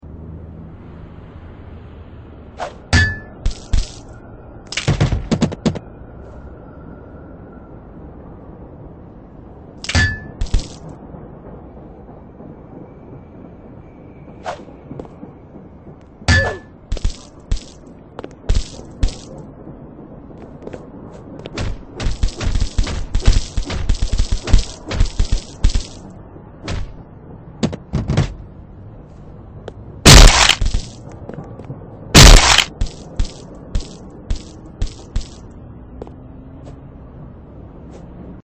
Violent Game With Blood sound effects free download